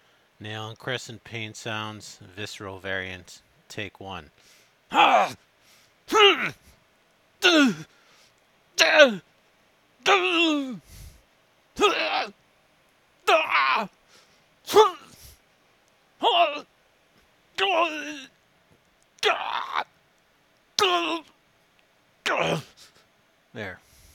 NaC.Pain.Visceral.Take1.ogg